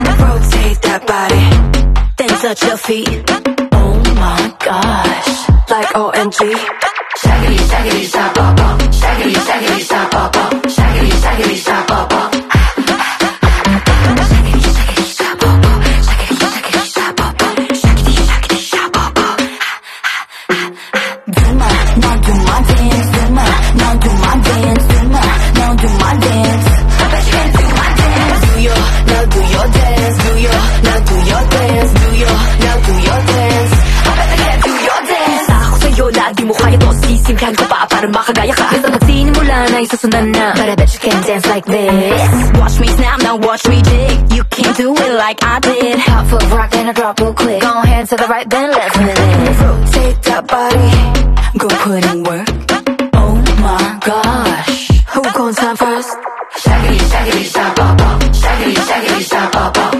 Don't miss BINI Gwen's Interview with Ogie Diaz 🧡